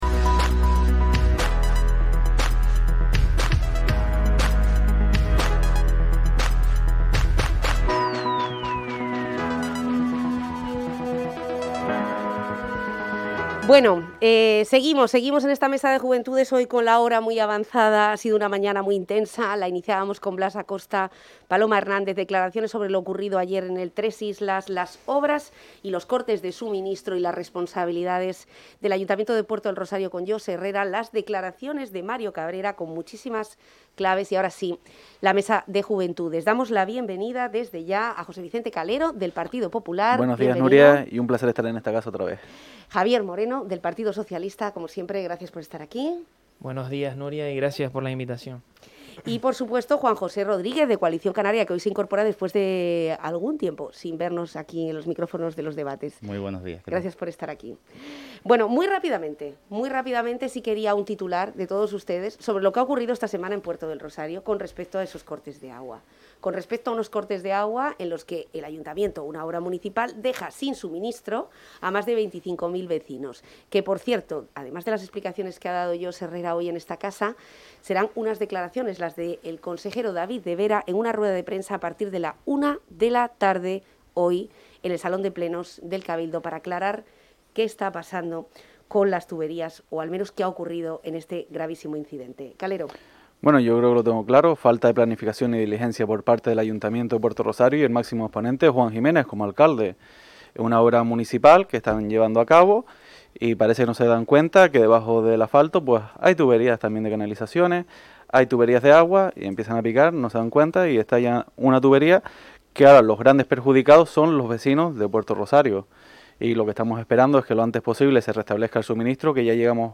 Unánimemente se han pronunciado en el apoyo a los trabajadores y la entrevista completa se puede escuchar aquí: